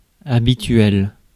Ääntäminen
Synonyymit courant ordinaire Ääntäminen France Tuntematon aksentti: IPA: /a.bit.ɥɛl/ Haettu sana löytyi näillä lähdekielillä: ranska Käännöksiä ei löytynyt valitulle kohdekielelle.